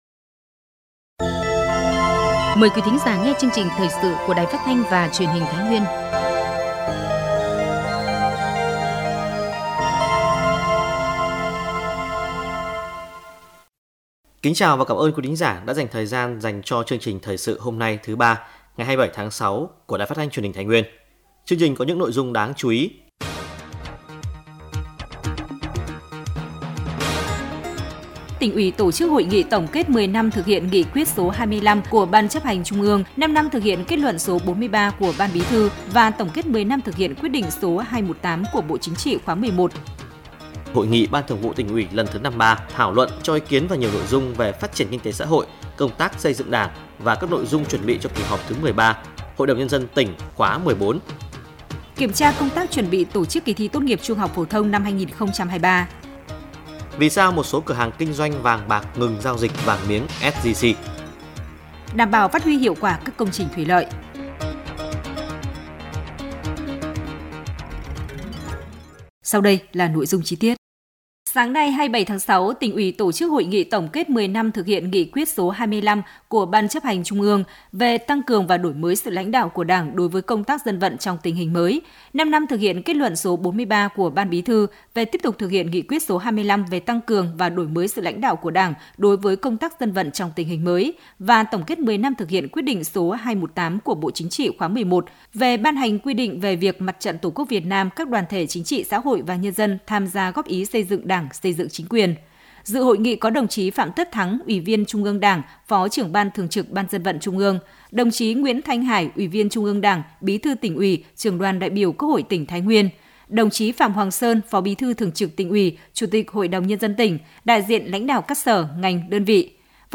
Thời sự tổng hợp Thái Nguyên ngày 06/7/2023